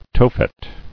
[To·phet]